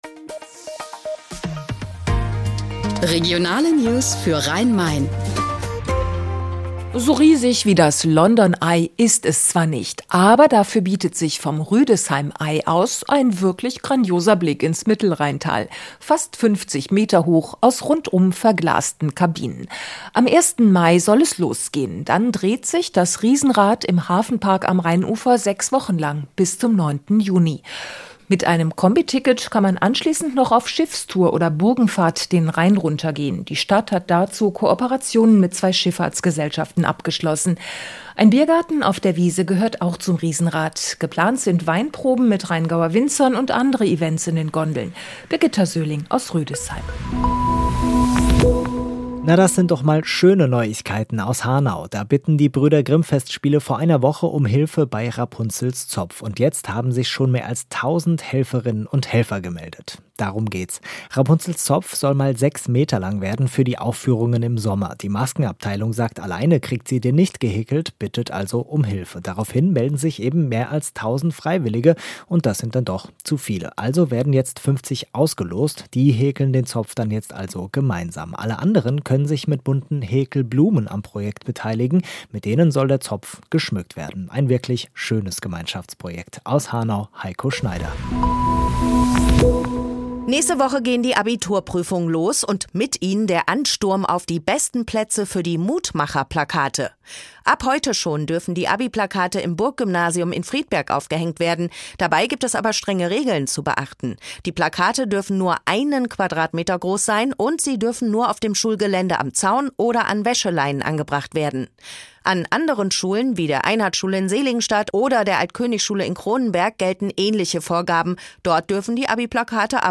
Mittags eine aktuelle Reportage des Studios Frankfurt für die Region